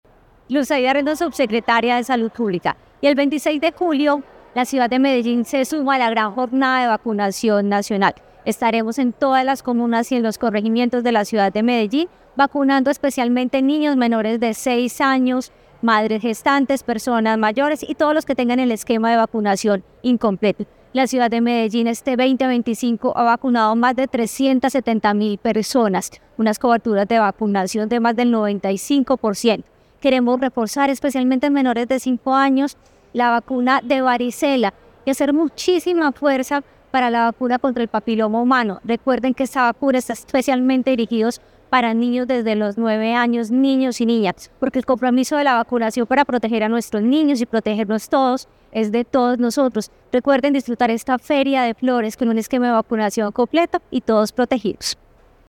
Declaraciones-de-la-subsecretaria-de-Salud-Publica-Luz-Aida-Rendon.mp3